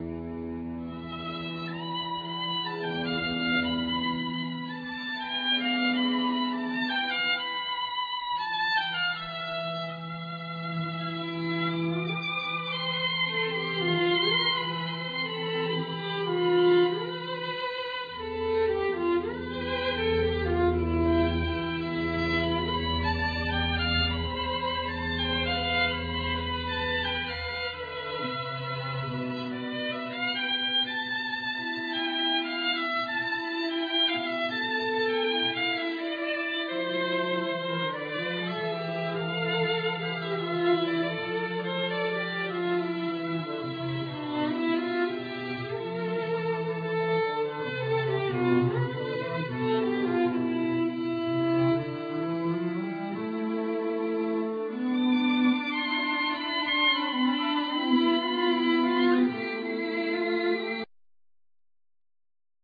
Soprano & Alto sax,Flute, Sampler
Violin
Cello
Double bass
Piano